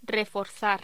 Locución: Reforzar
Sonidos: Voz humana